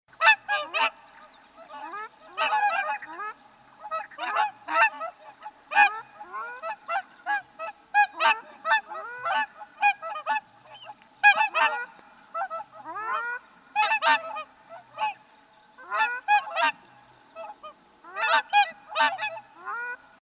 Звуки лебедей
Вы можете слушать или скачать их голоса, шум крыльев и плеск воды в высоком качестве.